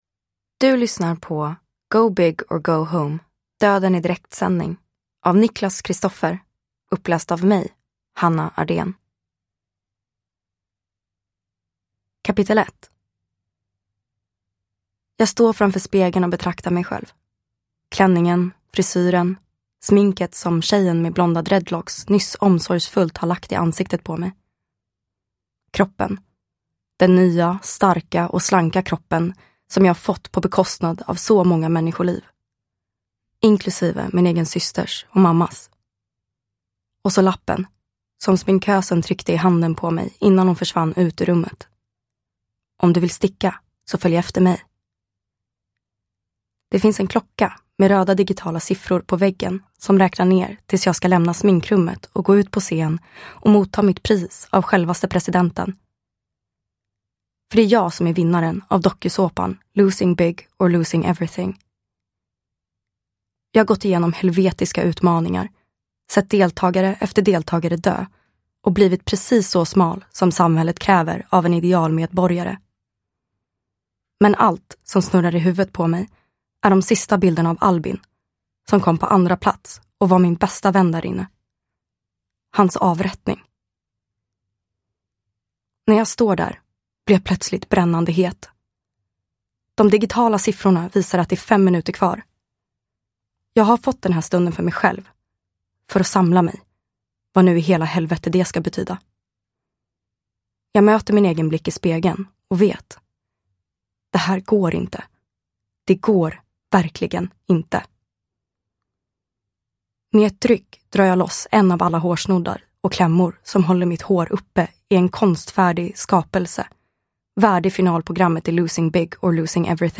Uppläsare: Hanna Ardéhn